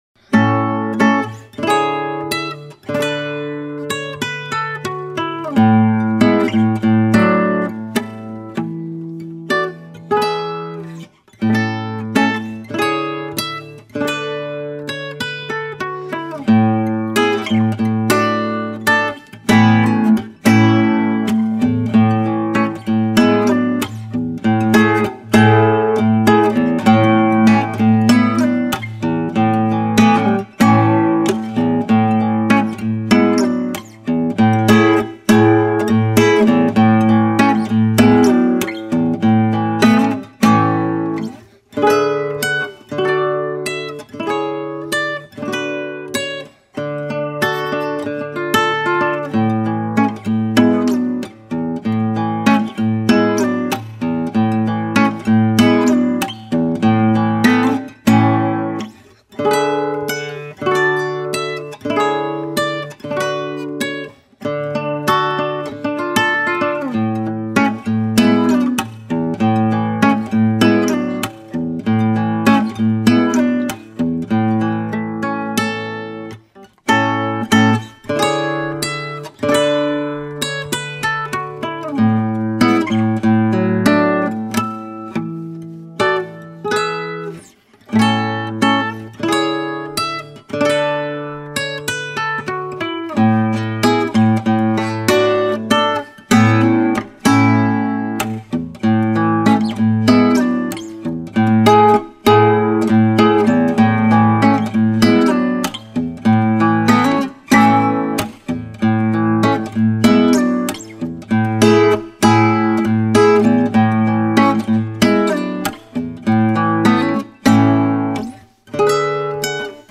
Older pieces made with older audio gear…